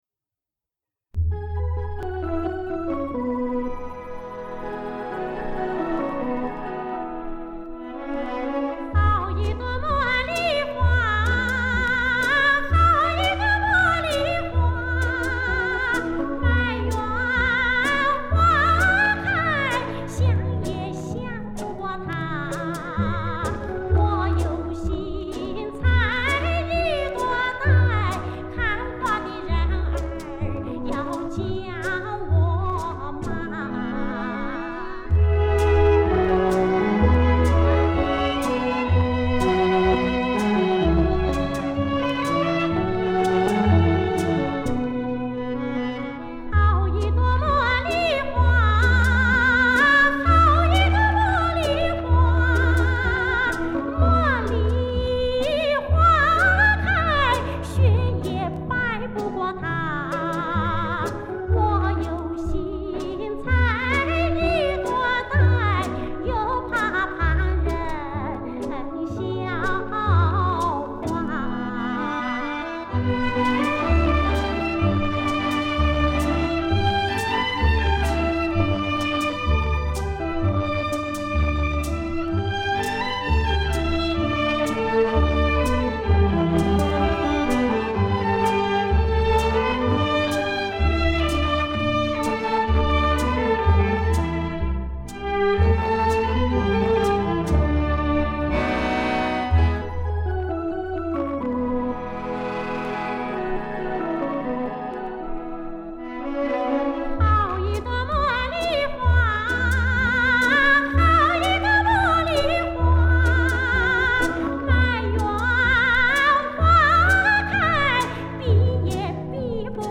有网友曾在社区上传过此歌薄膜单声道版本，这次上传立方体声版本